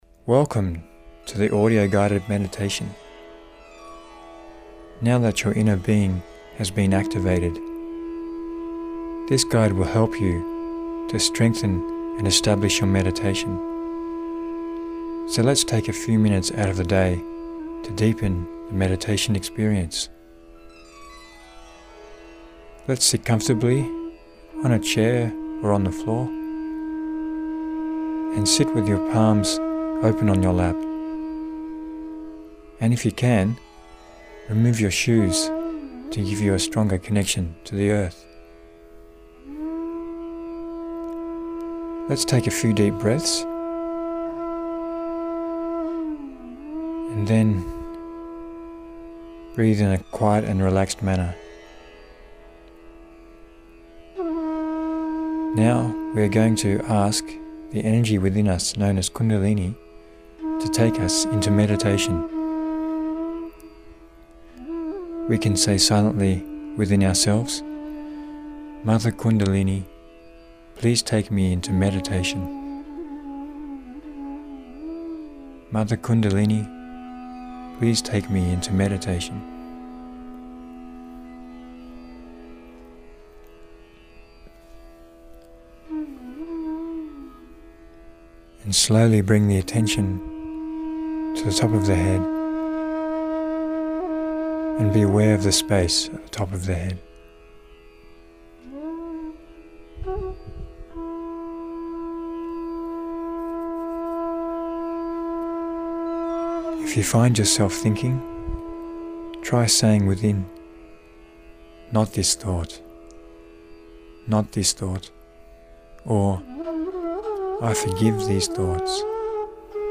Audio guide for meditation (5mins:10s)
Guided-Meditation.mp3